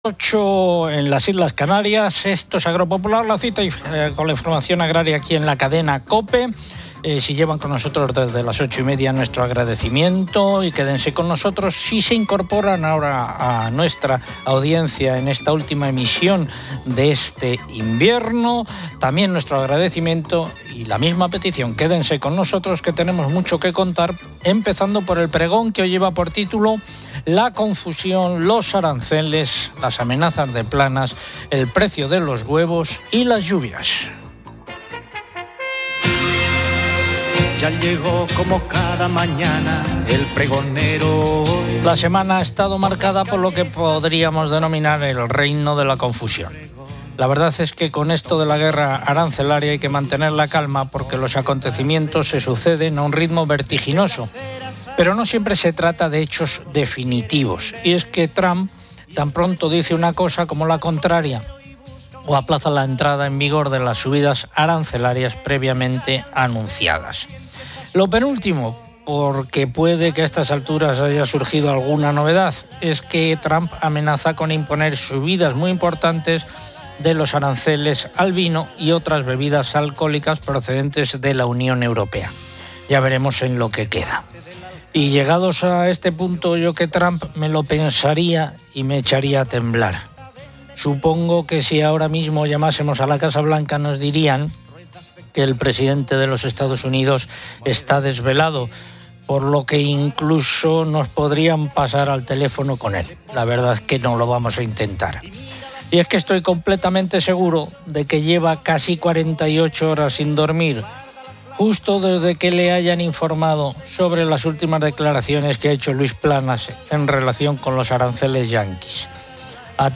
Agropopular es el programa decano de la radio española: